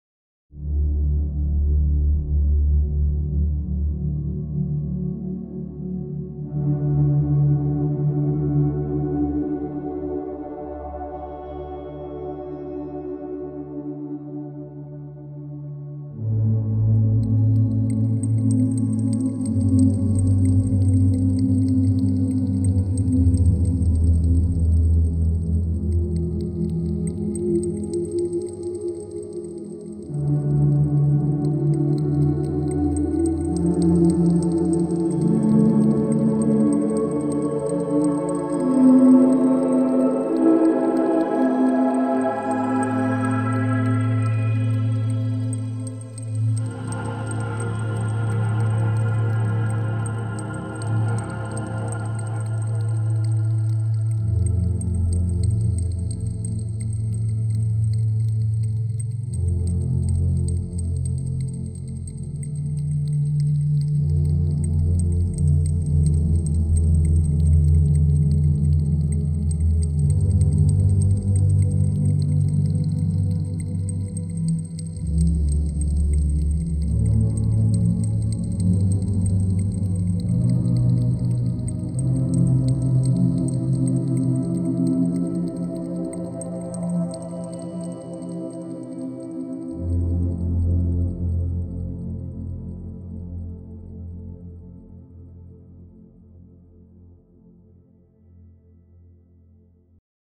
Another ambient song.